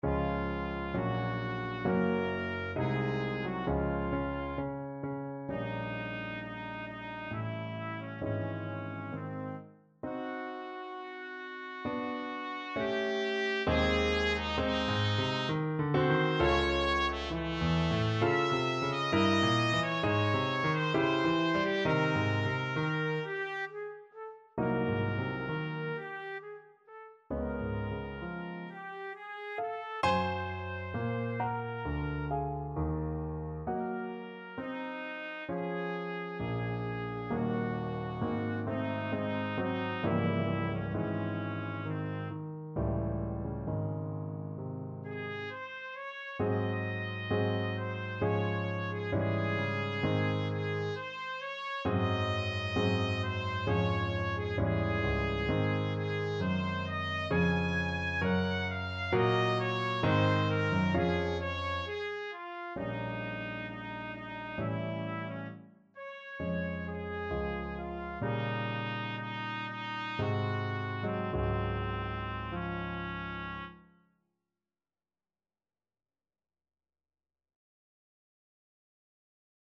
Trumpet
Db major (Sounding Pitch) Eb major (Trumpet in Bb) (View more Db major Music for Trumpet )
3/4 (View more 3/4 Music)
=66 Andante sostenuto
Classical (View more Classical Trumpet Music)